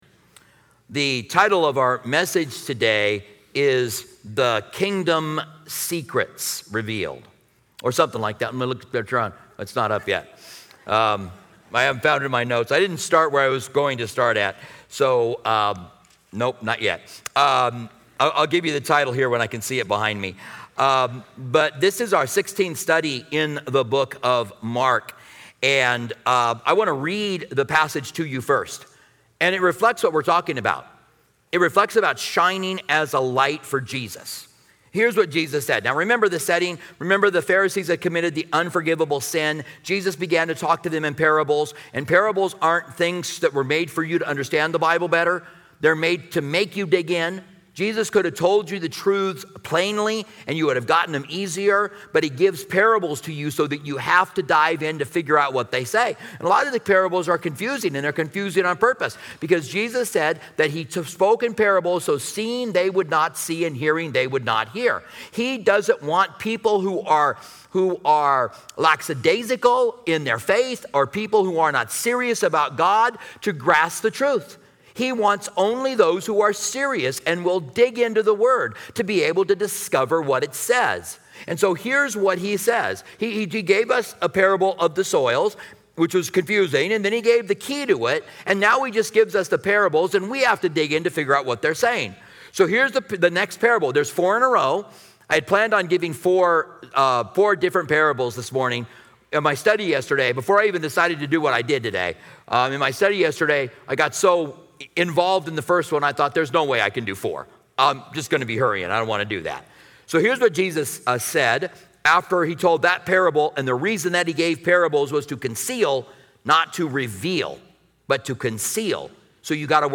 Addressing themes such as the sources of light—Jesus, believers, and Scripture—this sermon emphasizes that hiding our faith is counterproductive. Key takeaways include living transparently in Christ's light, understanding the principles of spiritual investment, and tuning into God's voice.